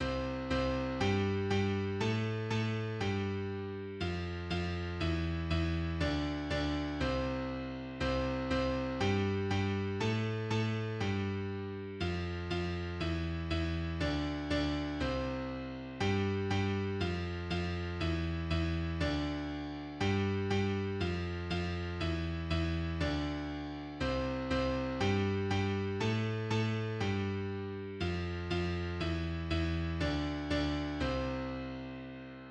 "Twinkle, Twinkle, Little Star" melody doubled in four octaves.
Play piano